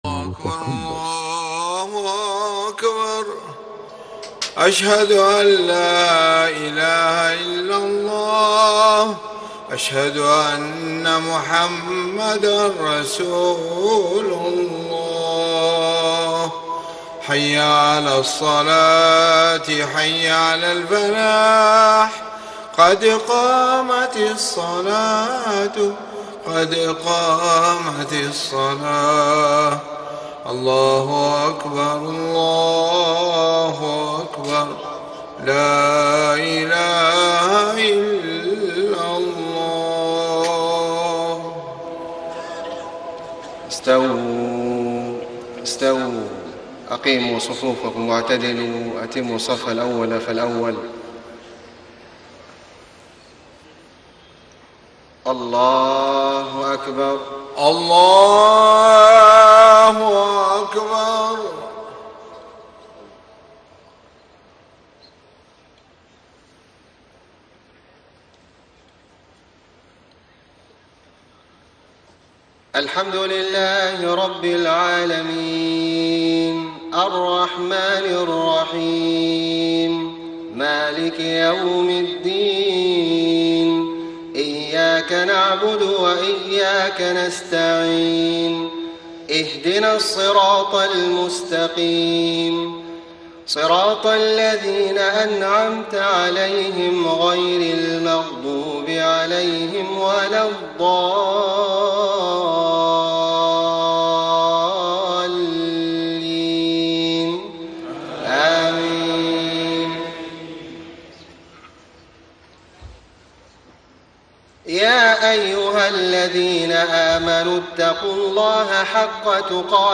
صلاة العشاء 4-5-1434 من سورة آل عمران > 1434 🕋 > الفروض - تلاوات الحرمين